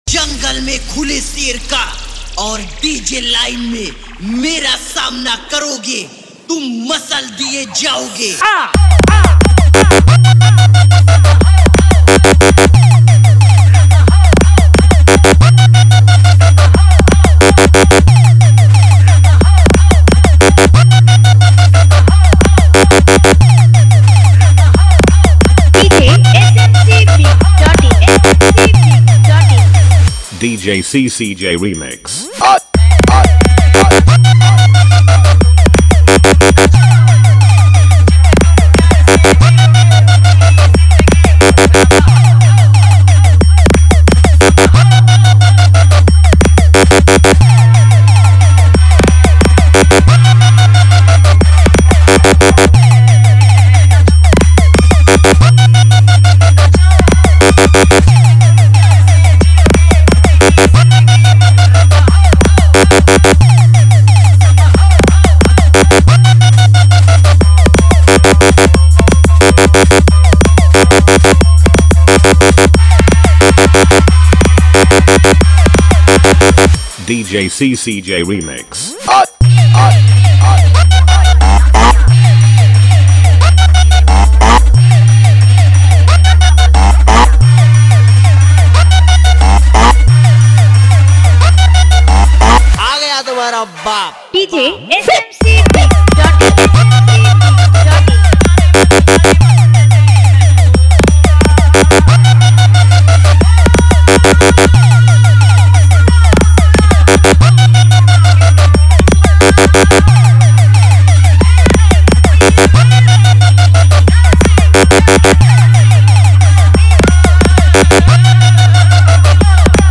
Competition Mix